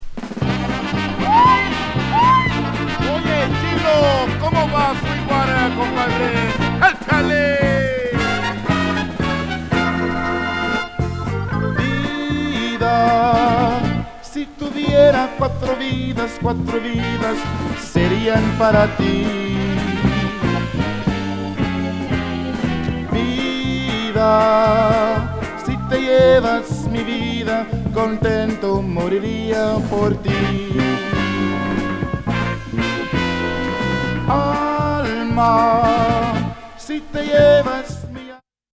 テックス・メックスのオルケスタ（ホーン入りオーケストラ）名グループ
イントロの掛け声、ホーン、メキシカンなボーカルも陽気で気持ちよ〜い